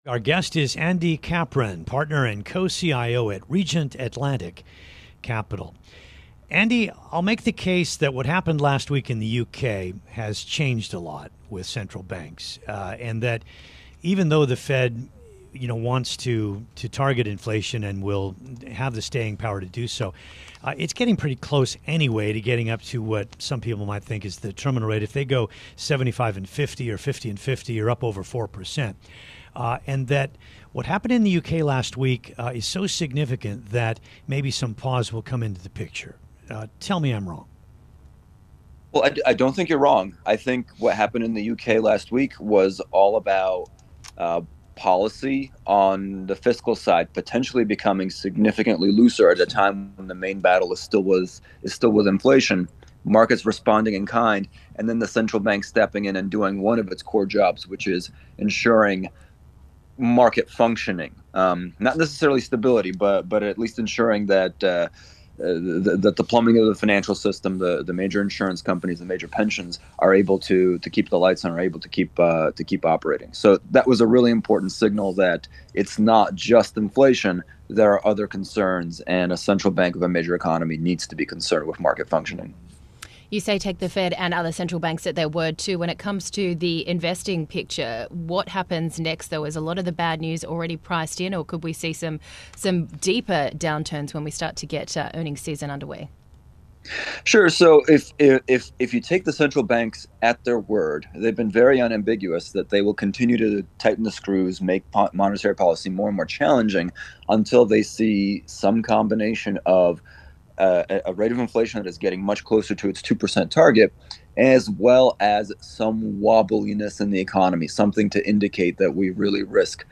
(Radio)